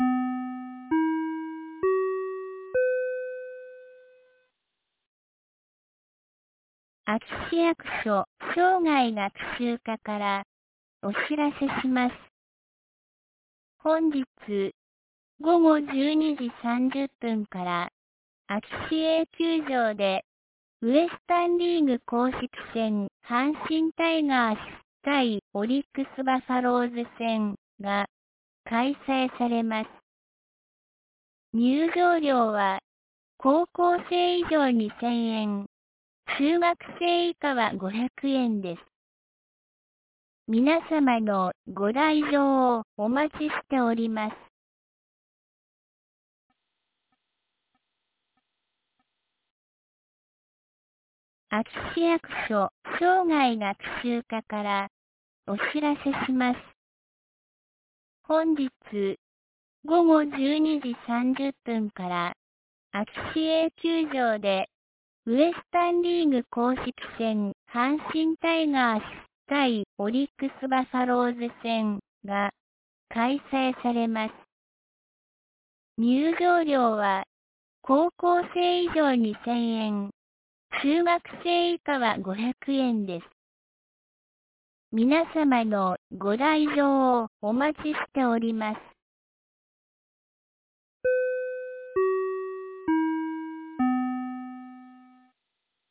2022年09月11日 10時01分に、安芸市より全地区へ放送がありました。